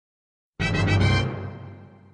Sound_BuildingAddition.mp3